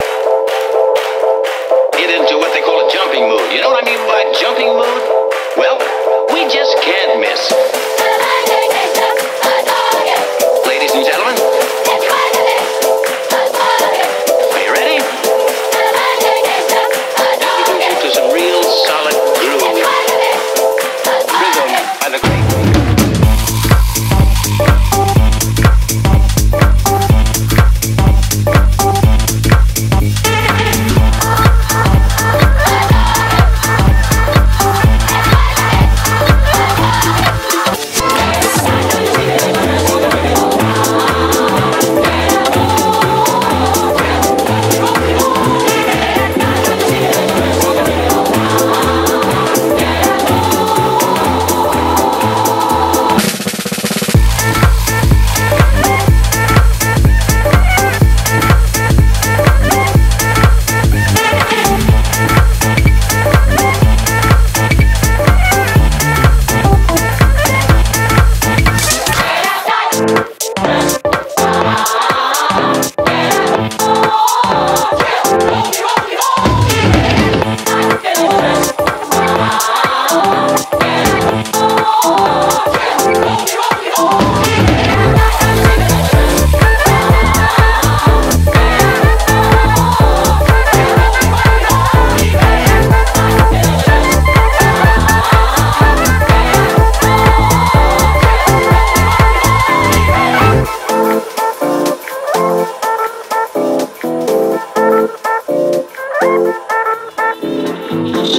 BPM124-124
Audio QualityPerfect (Low Quality)